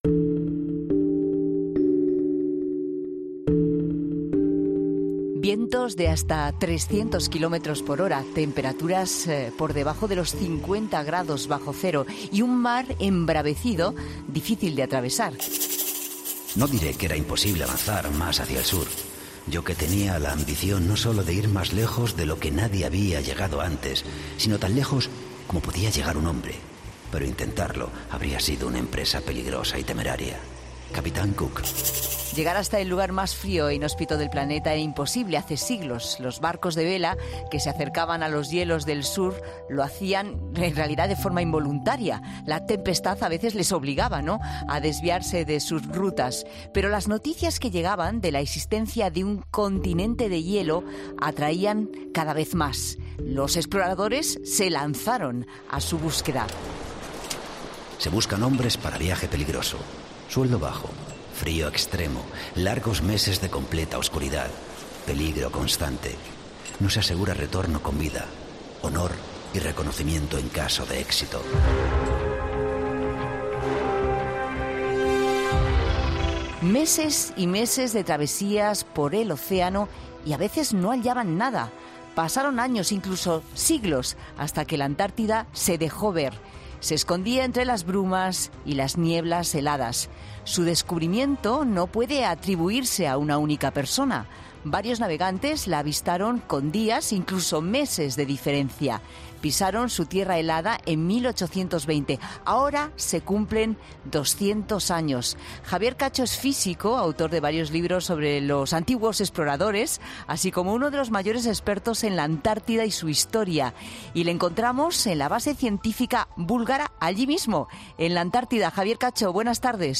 La Tarde de COPE ha conectado con la Antártida.